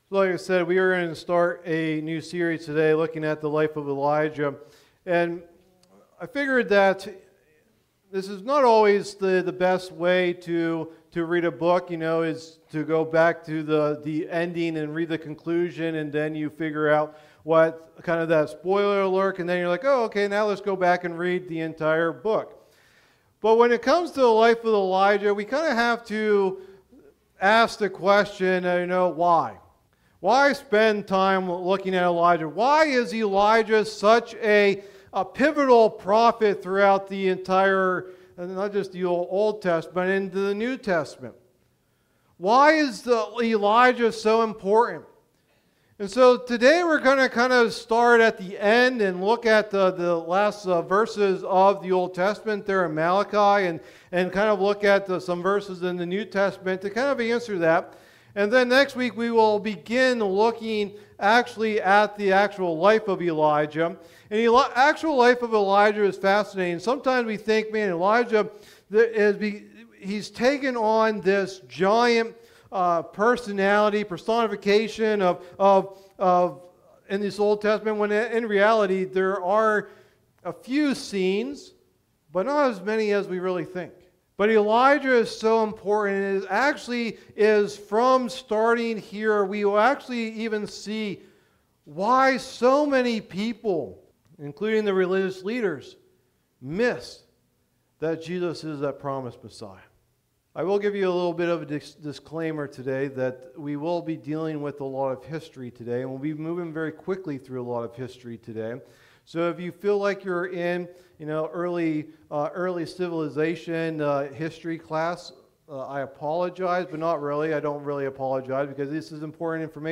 Message #1 of the "Life of Elijah" teaching series